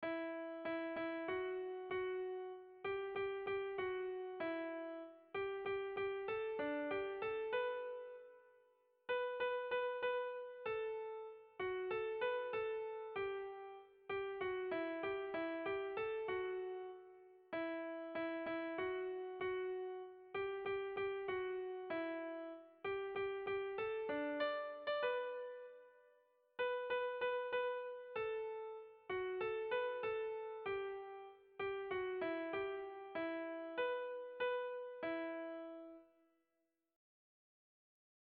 Sentimenduzkoa
Zortziko handia (hg) / Lau puntuko handia (ip)